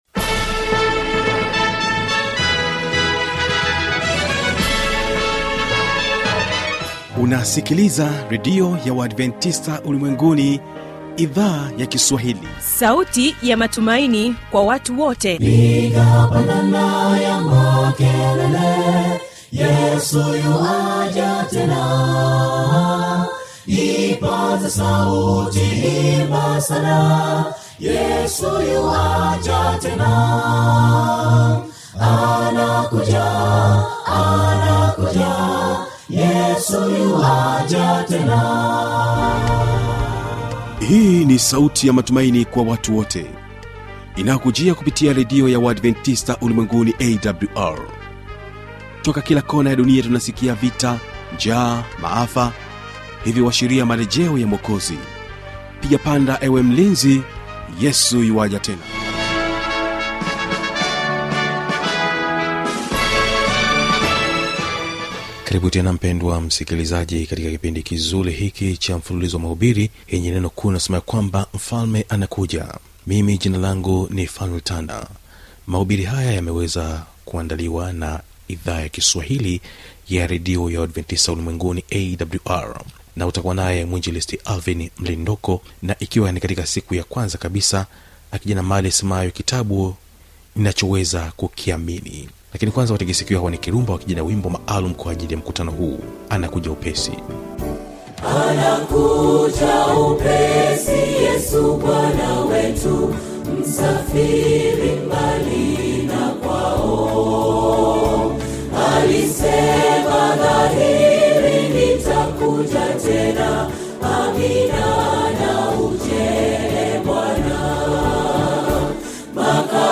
Mahubiri